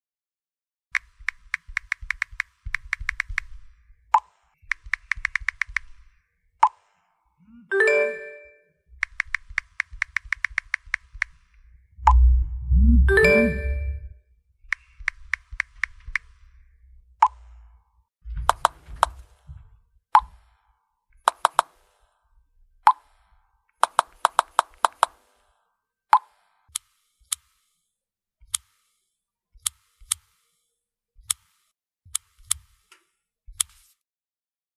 Retro SMS Buzz Sound Button: Unblocked Meme Soundboard